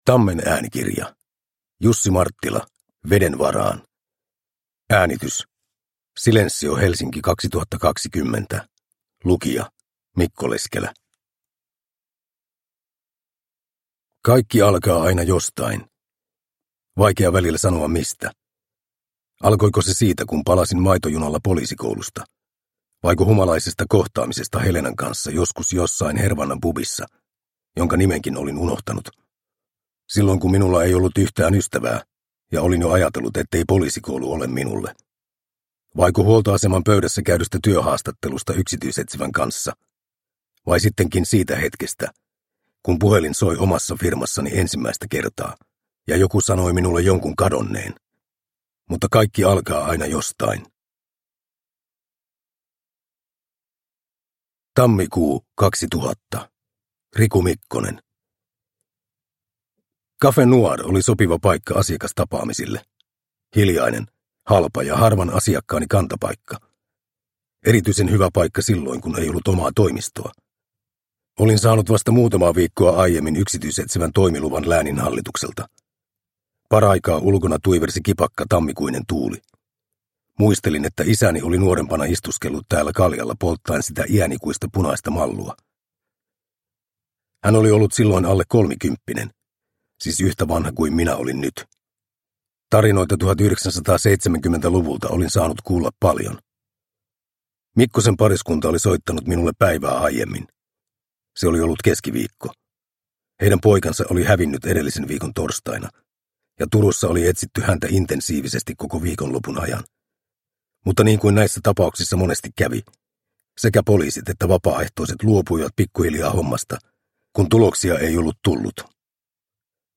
Veden varaan – Ljudbok – Laddas ner